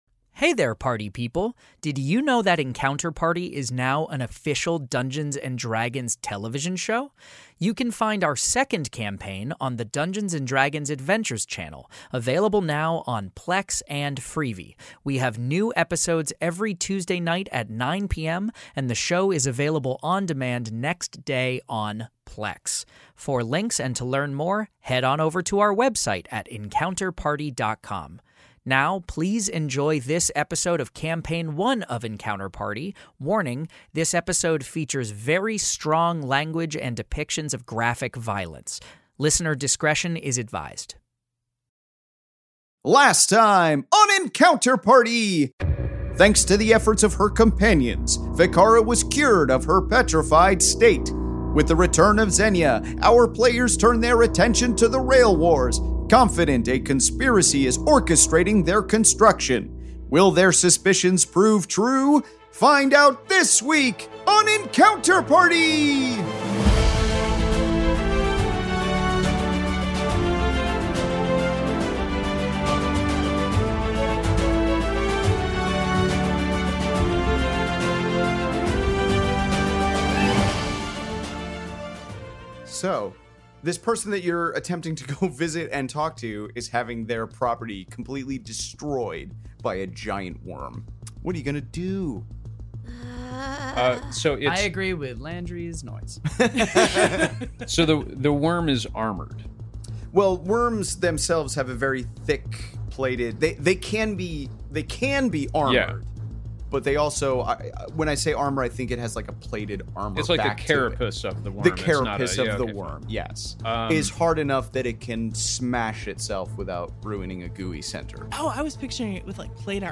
Fantasy Mystery Audio Adventure
five actors and comedians